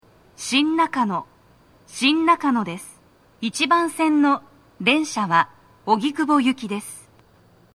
スピーカー種類 TOA天井型
1番線 荻窪方面 到着放送 【女声